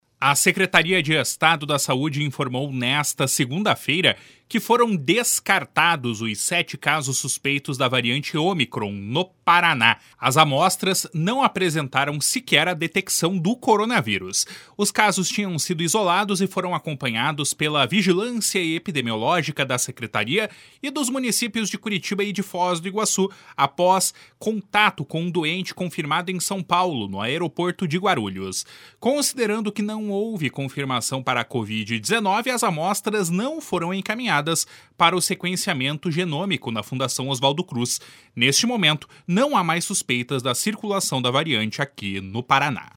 Neste momento, não há mais suspeitas da circulação da variante no Paraná. (Repórter: